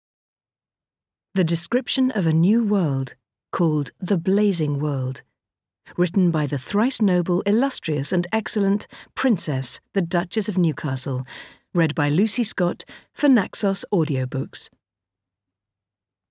The Blazing World (EN) audiokniha
Ukázka z knihy